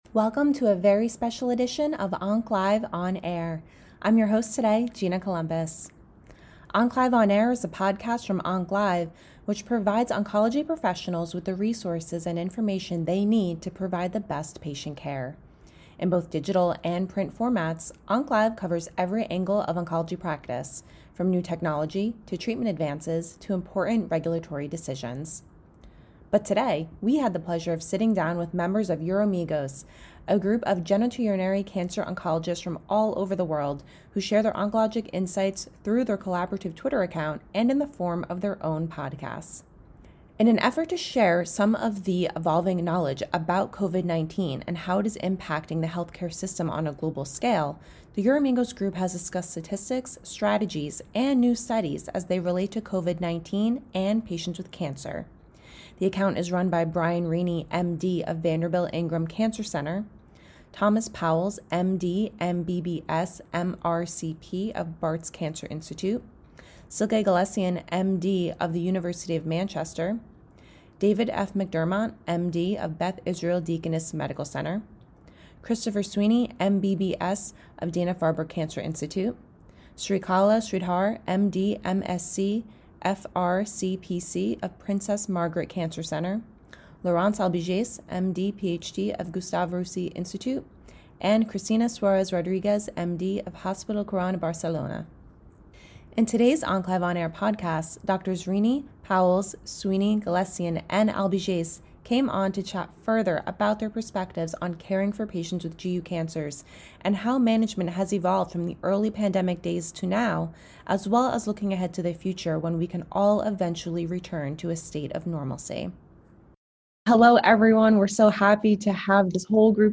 We had the pleasure of sitting down with members of Uromigos, a group of genitourinary cancer oncologists from all over the world who share their oncologic insights through their collaborative Twitter account and in the form of their own podcasts.